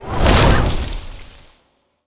nature_spell_vines_whoosh_01.wav